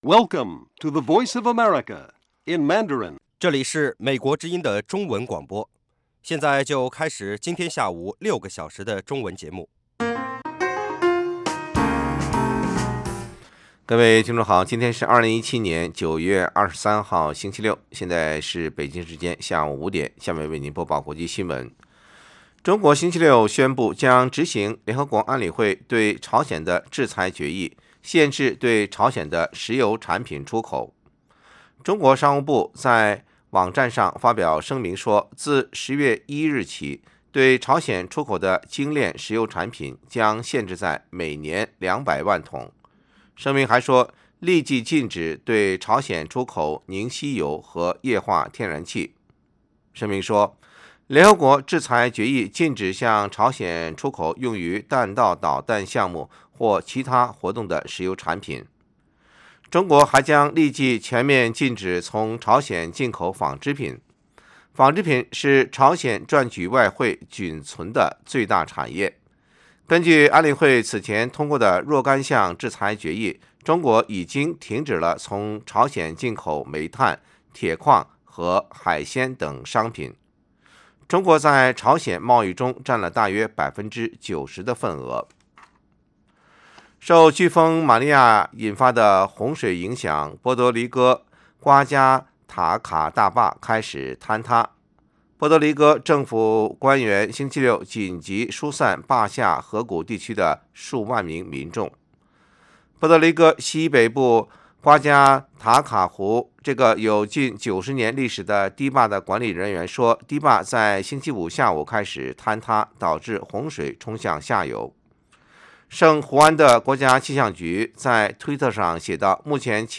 北京时间下午5-6点广播节目。广播内容包括国际新闻，收听英语，以及《时事大家谈》(重播)